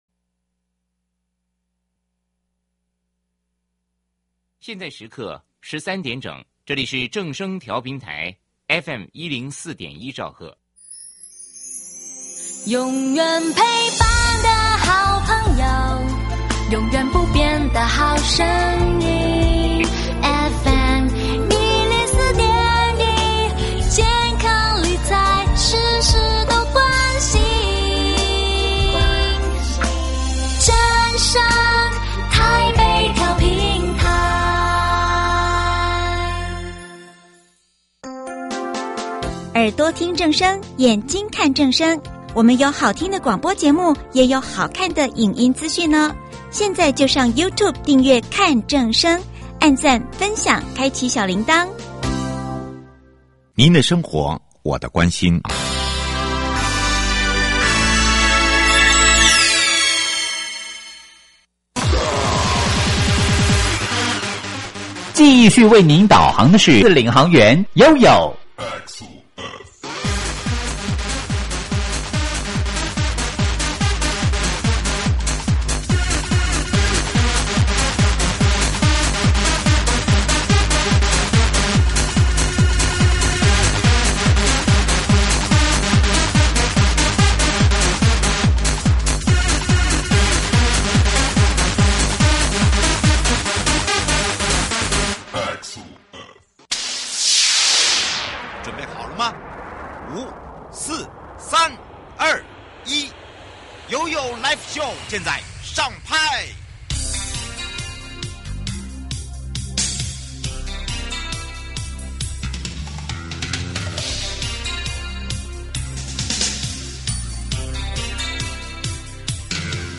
受訪者： 1.國土署都市基礎工程組 2.新竹縣政府交通旅遊處 陳盈州處長(一)